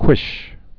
(kwĭsh)